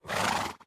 sounds / mob / horse / idle2.mp3